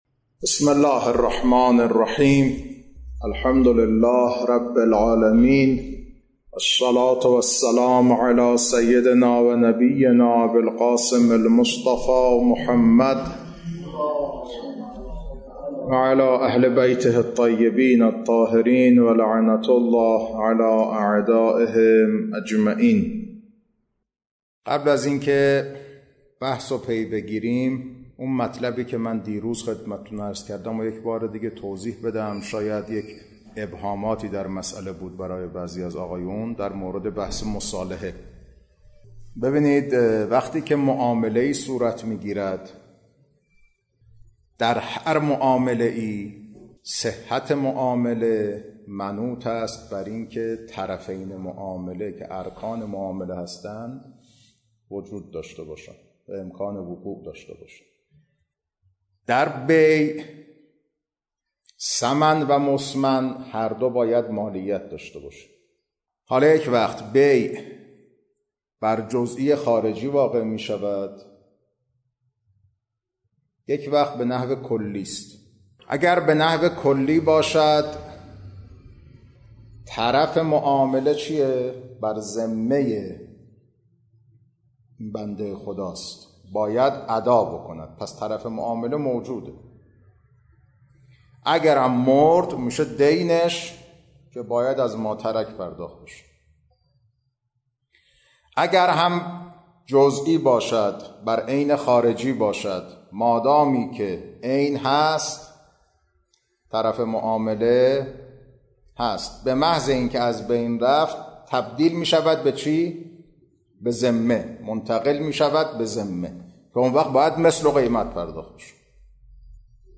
خارج فقه، بحث نکاح